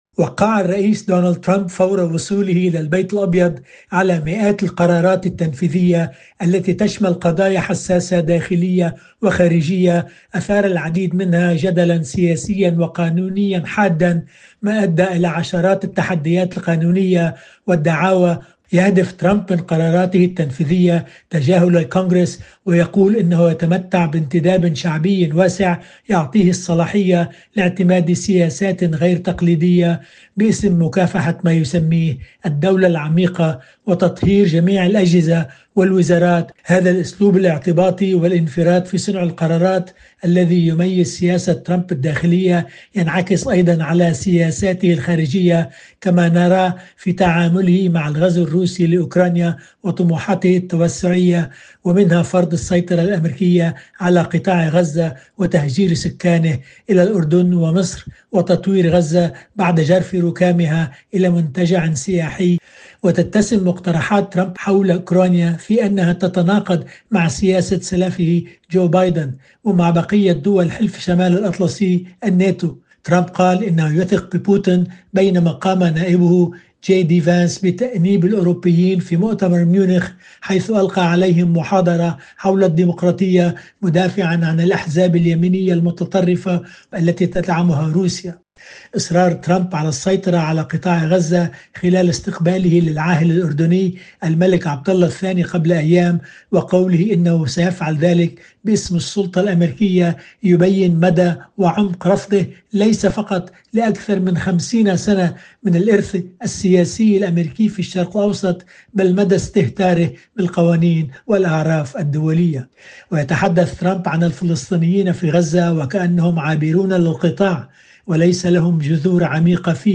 فقرة إخبارية تتناول خبراً أو حدثاً لشرح أبعاده وتداعياته، تُبَثّ على مدار الأسبوع عند الساعة الرابعة والربع صباحاً بتوقيت باريس ويُعاد بثها خلال الفترات الإخبارية الصباحية والمسائية.